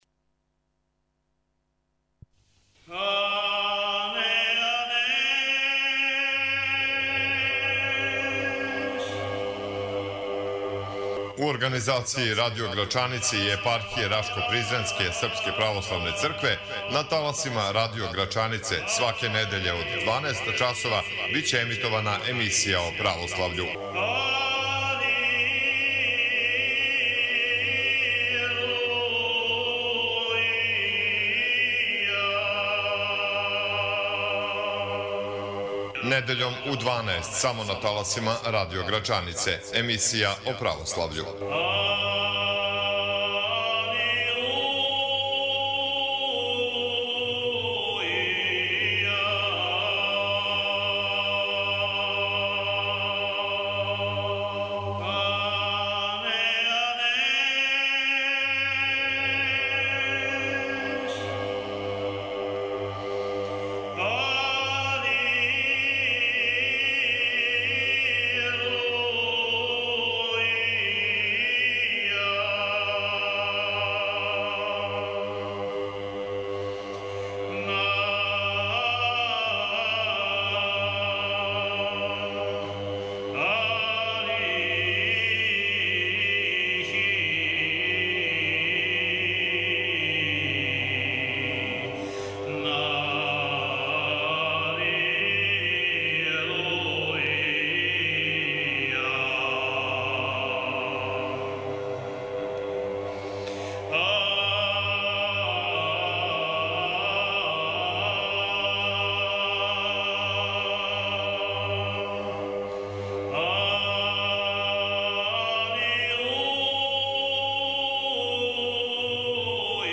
Емисија о Православљу радио Грачанице – гост Епископ рашко-призренски Теодосије | Званична интернет презентација | Епархија Рашко-призренска српске православне цркве
Данас, на Томину недељу, гост девете емисије о Православљу радио Грачанице био је Његово преосвештенство Епископ рашко-призренски и ксовско-метохијски Теодосије. Владика Теодосије је ову прилику искористио да свим верницима поверене му Епархије честита највећи хришћански празник Васкрс, да објасни суштину и смисао данашњег празника али и да изнесе своје утиске са поклоњења у Свету Земљу.